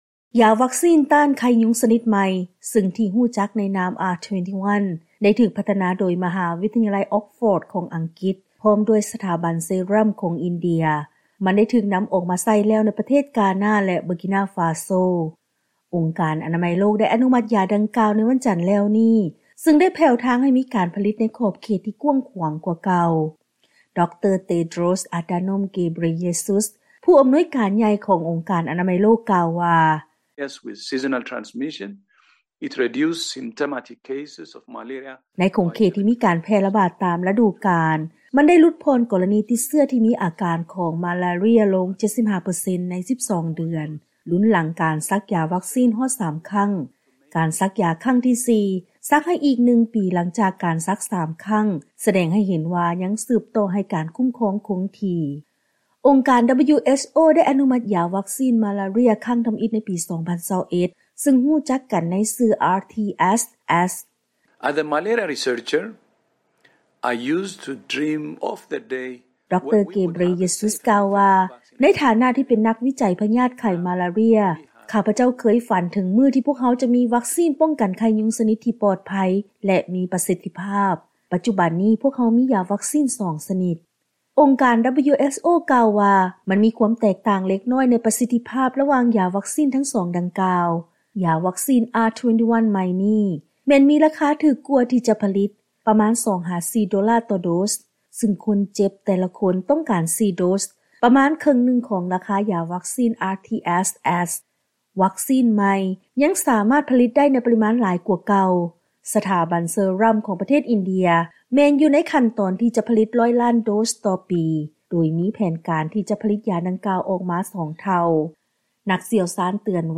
Dr. Tedros Adhanom Ghebreyesus, World Health Organization Director-General.